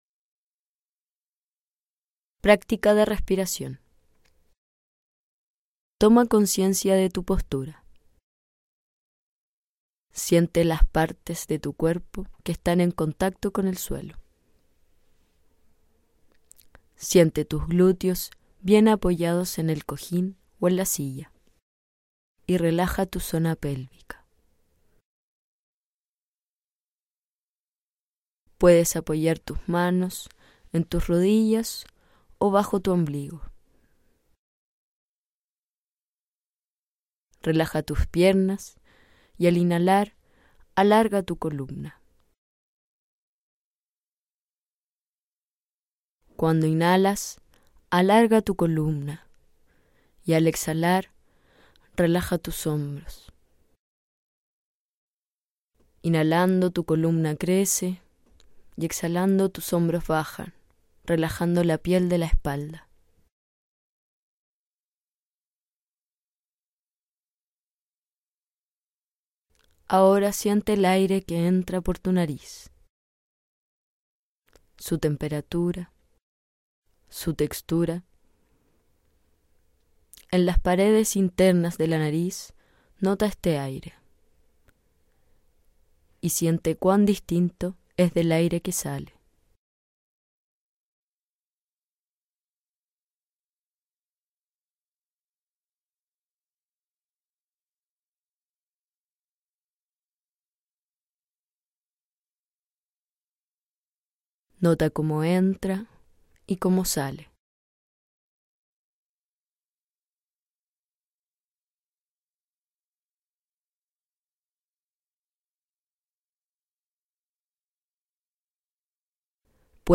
Ejercicios y materiales para practicar en casa (audios con prácticas guiadas y libro ejercicios).
Practica de respiracion Descarga
2-prctica-de-respiracin.mp3